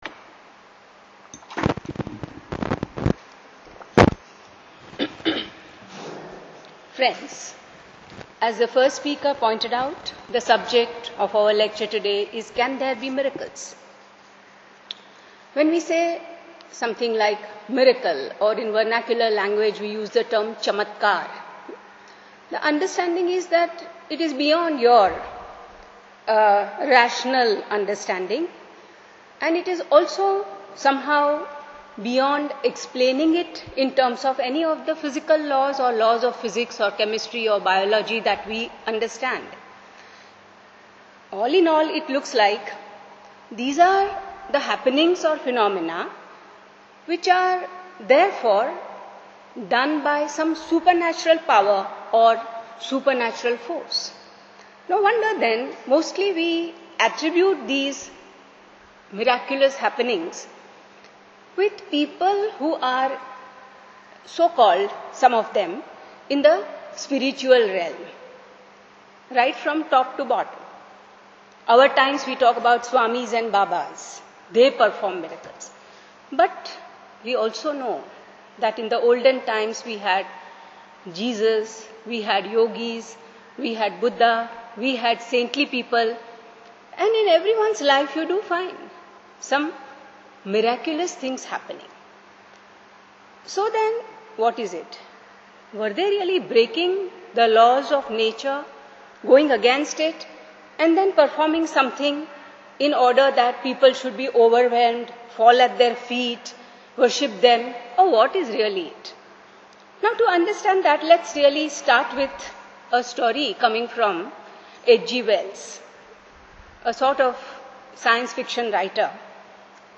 Uploaded Audio Lecture: Can There Be Miracles